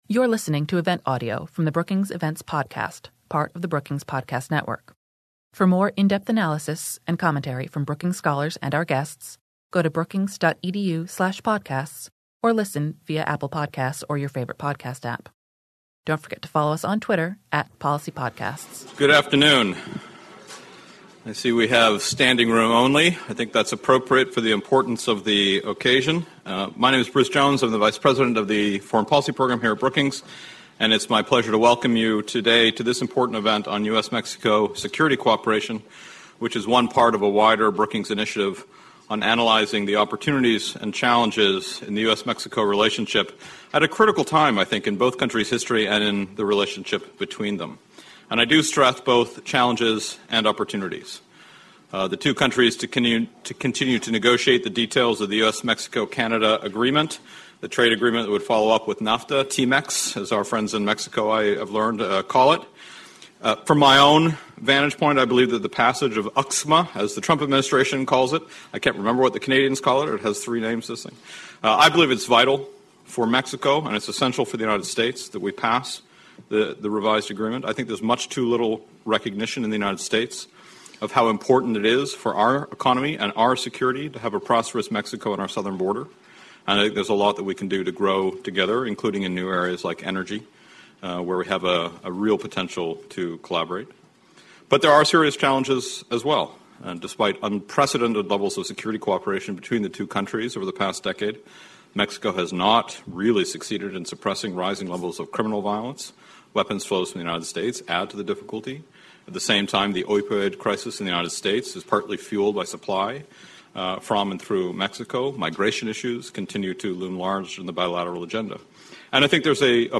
Despite unprecedented security cooperation with the US, Mexico continues to face issues related to criminality, human rights violations, and rule of law. On March 27, Brookings hosted a public discussion on the opportunities and challenges for Presidents Donald Trump and Andrés Manuel López Obrador.
Panel discussion